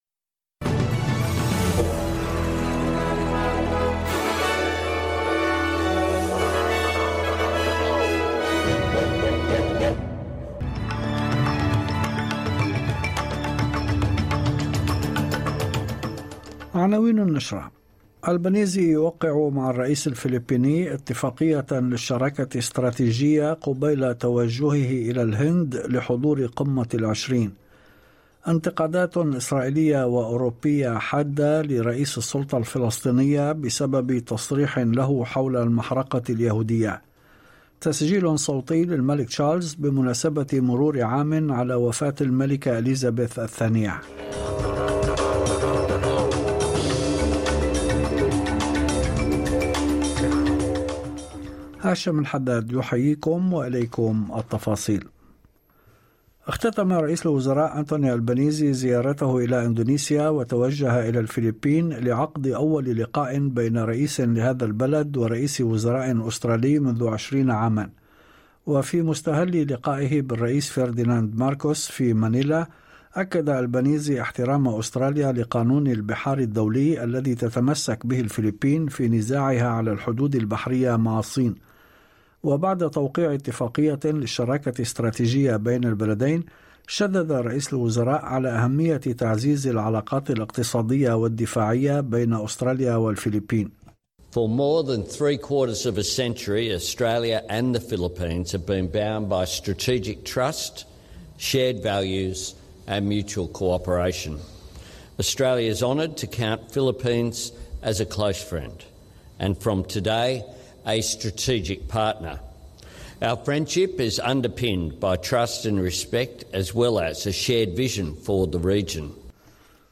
نشرة أخبار المساء 8/9/2023
تسجيل صوتي للملك تشارلز بمناسبة مرور عام على وفاة الملكة أليزابيث الثانية.